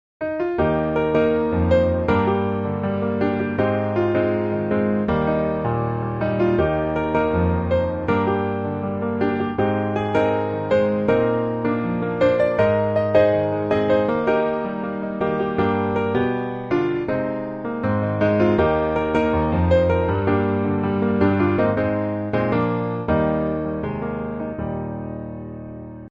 Eb Majeur